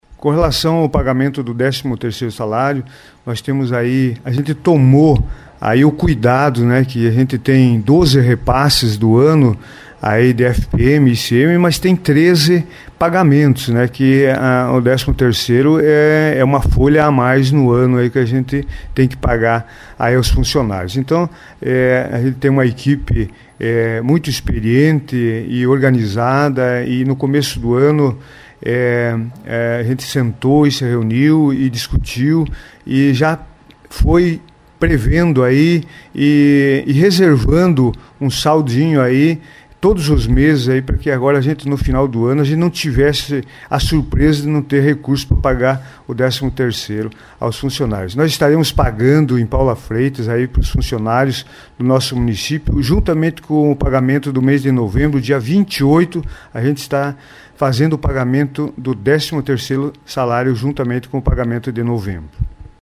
O administrador comentou em entrevista para a Rádio Colmeia, as ações focadas e positivas para a cidade.
Acompanhe as palavras do prefeito quefala sobre a finança da cidade e destaca o pagamento.